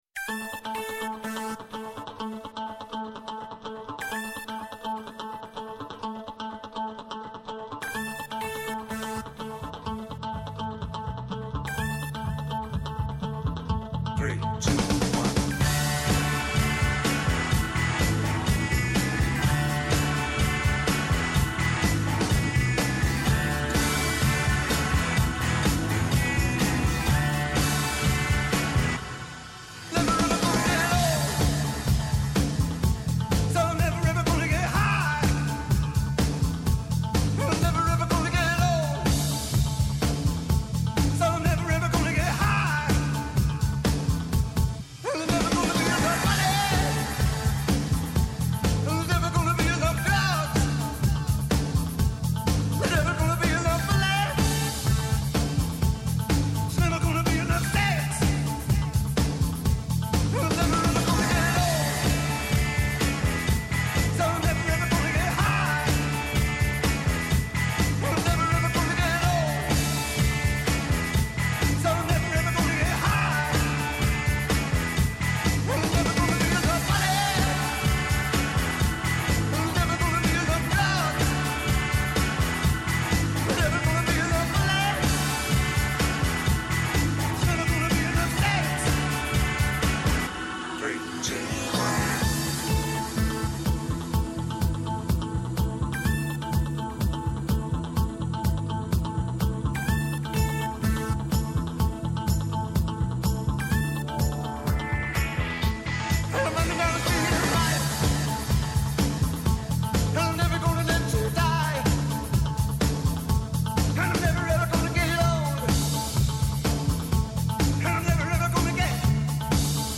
Kαλεσμένοι στην εκπομπή είναι κυβερνητικοί αξιωματούχοι, επιχειρηματίες, αναλυτές, τραπεζίτες, στελέχη διεθνών οργανισμών, πανεπιστημιακοί, φοροτεχνικοί και εκπρόσωποι συνδικαλιστικών και επαγγελματικών φορέων, οι οποίοι καταγράφουν το σφυγμό της αγοράς και της οικονομίας.